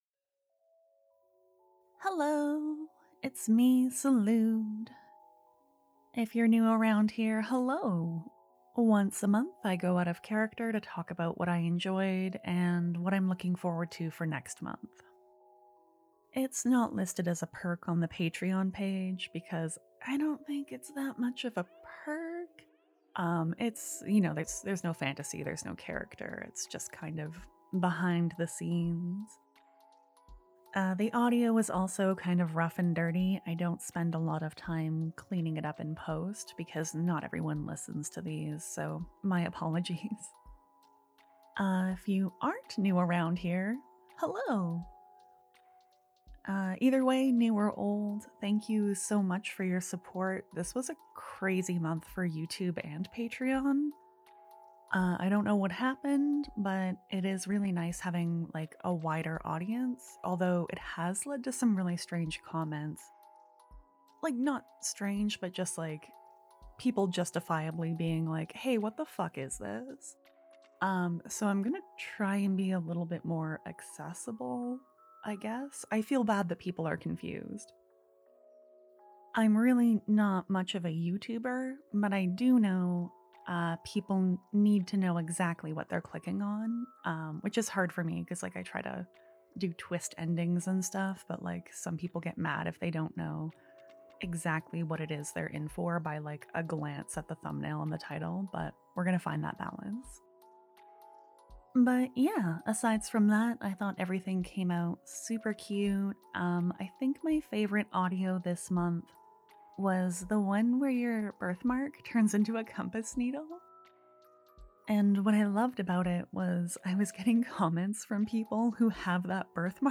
I go out of character once a month to talk about my stories and what I liked from this month, and what I want to do next month. It's just boring update stuff and a chance for me to say thank you with my real voice rather than my written words~Just as a heads up, these are pretty rough recordings that don't have a ton of time in post to get cleaned up and edit so the audio isn't amazing or anything~Here's a link to the video by Vox I mentioned~!